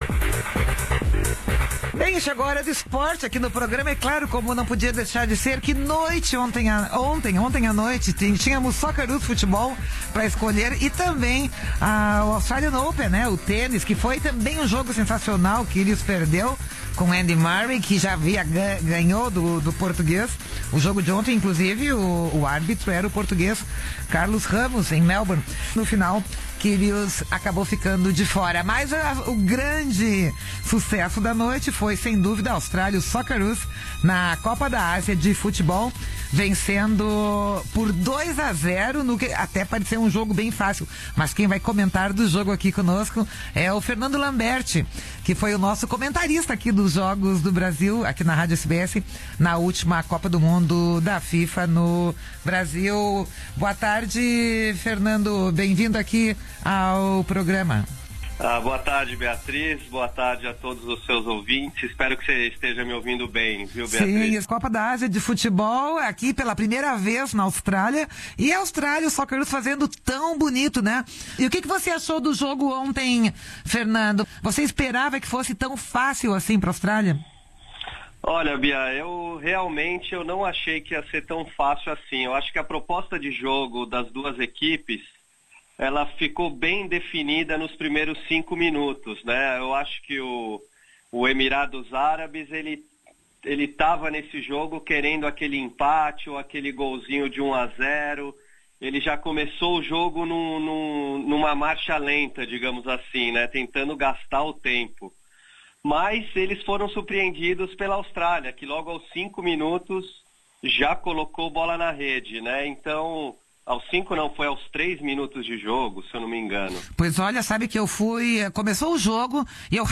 Conversamos ao vivo